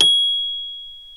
CELESTE 2 0E.wav